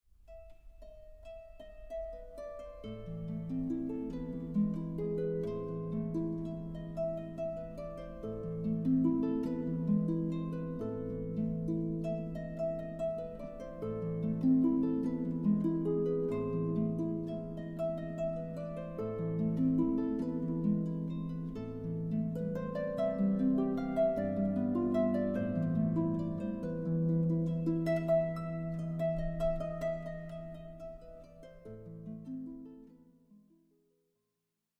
A beautiful cd with 77 minutes harp solo music.